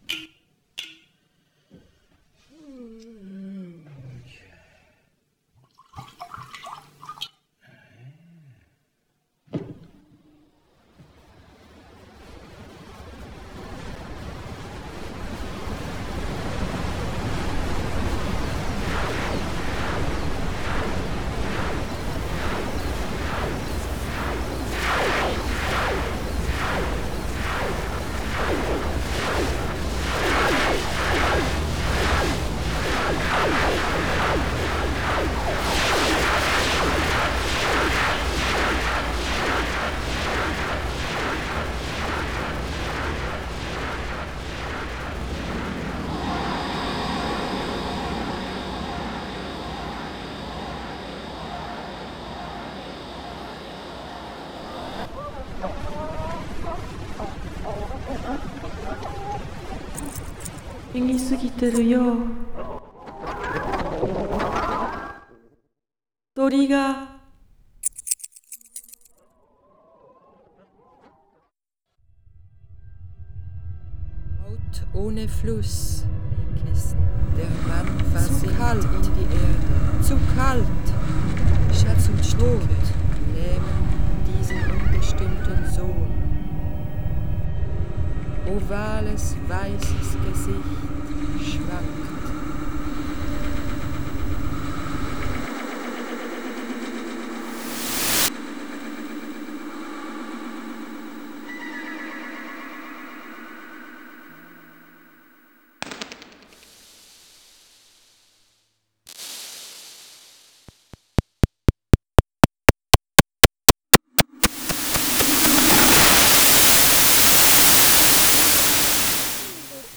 Pièces acousmatiques:
Création composée de jeux sur les timbres de voix d'enfants, d'adultes, jeux sur la sonorité et le poids des mots allemands, japonais, français, et de scènes acoustiques retravaillées